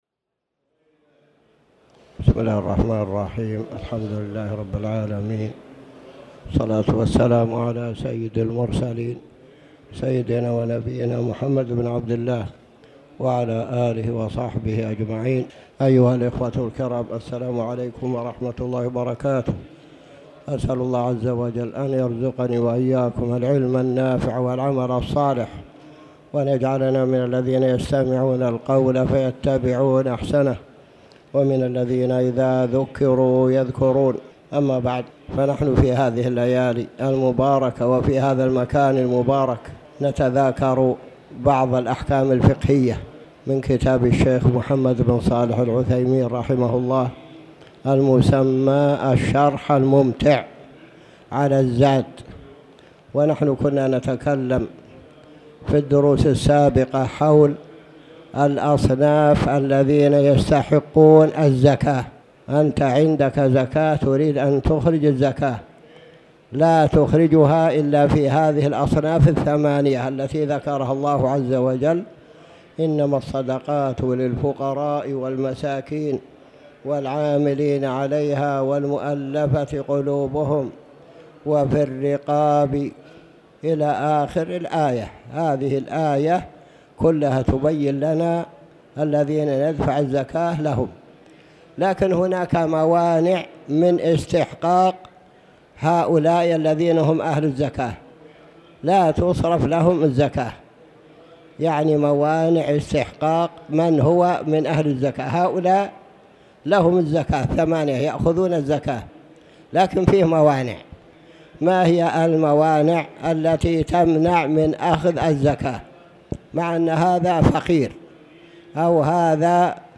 تاريخ النشر ٦ جمادى الأولى ١٤٣٩ هـ المكان: المسجد الحرام الشيخ